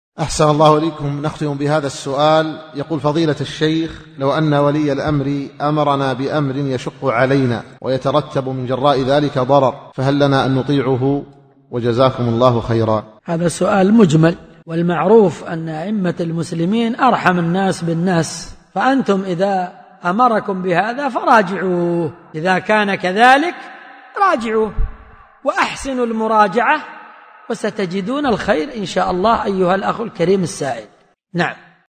ملف الفتوي الصوتي عدد الملفات المرفوعه : 1
من محاضرة : ( حق الراعي والرعية ) .